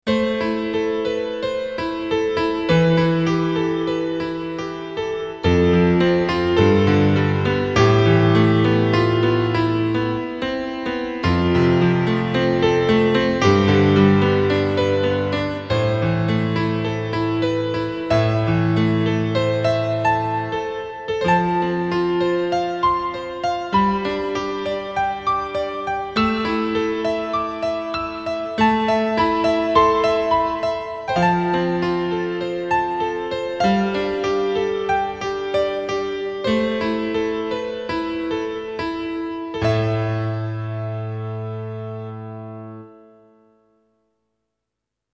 Piano Evolution is an exciting set of new piano sounds for your keyboard that are powered by 32MB of high quality stereo Concert Piano samples that span the entire range of your keyboard. Thanks to Piano Evolutions dual layer multisamples, the sound will respond in great detail when played even with the slightest touch, right up to the most dynamic heights.